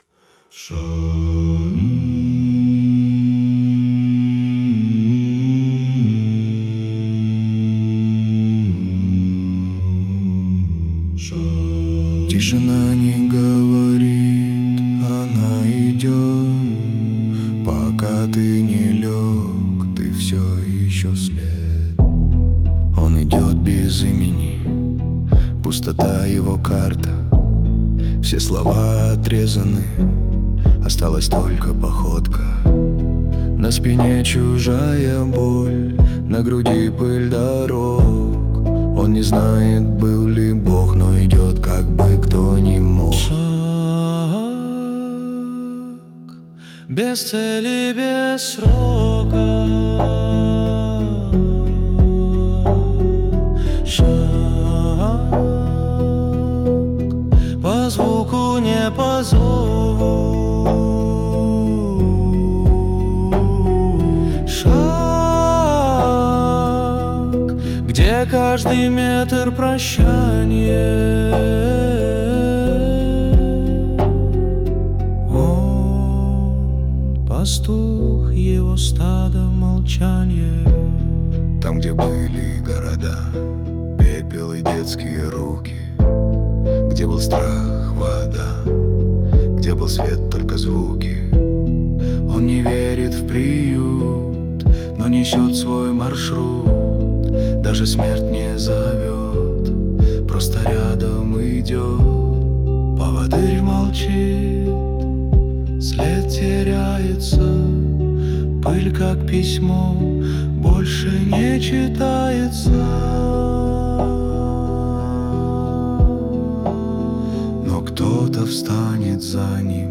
Музыка - дарк-фолк, эмбиент
Записано в комнате, где давно никто не говорит вслух